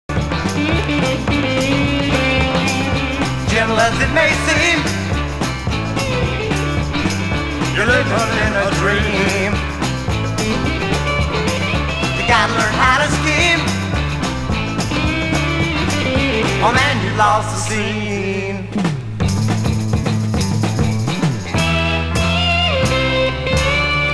はじめからあんなハードな芸風なのかと思ったらソウルのカヴァーやサイケポップ等まだまだ手探りの状態。
(税込￥2420)   PSYCH